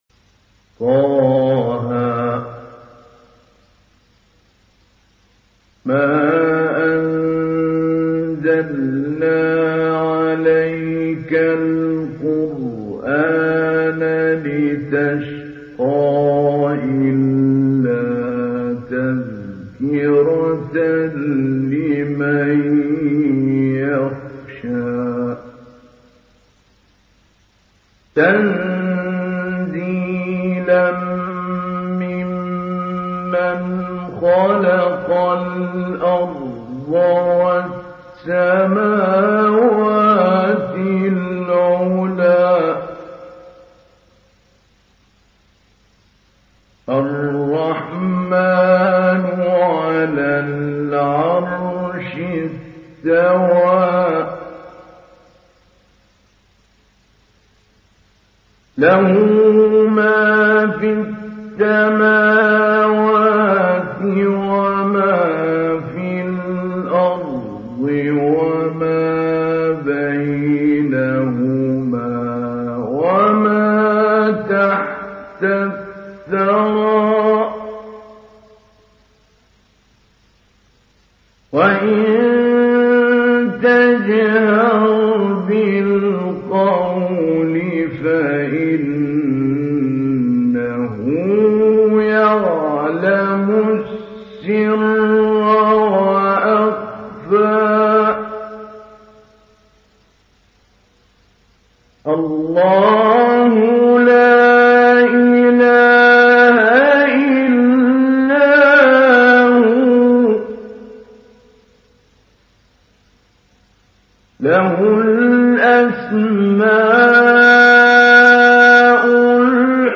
Download Surah Taha Mahmoud Ali Albanna Mujawwad